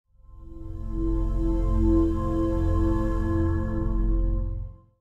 CB_Breath-Out_v01.mp3